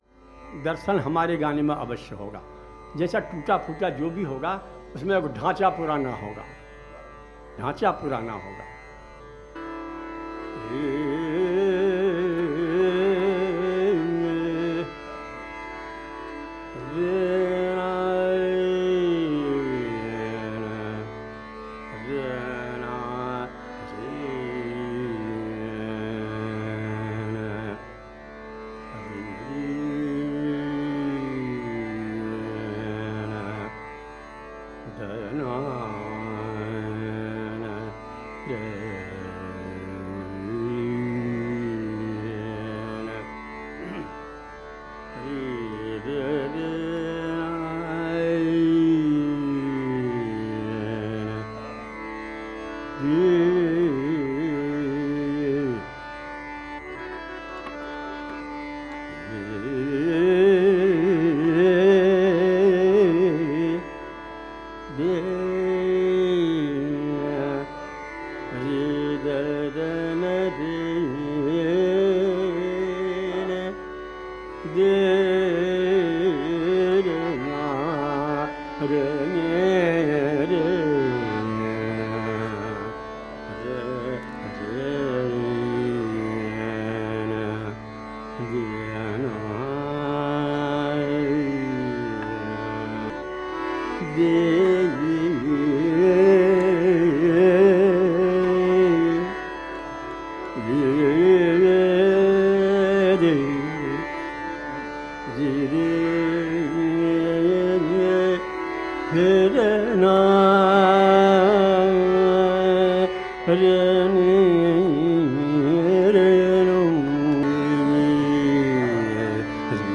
To mark the occasion, an excerpt of Jha-sahab’s recital in Kolkata (c. 2003) is offered. Tabla
harmonium